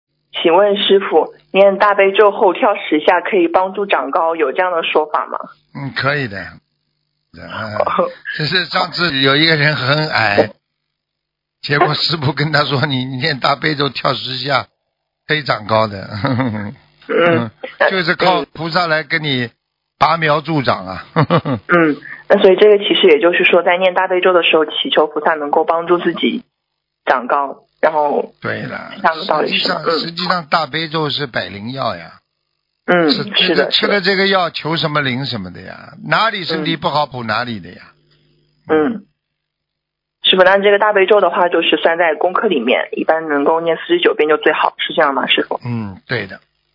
目录：☞ 2019年08月_剪辑电台节目录音_集锦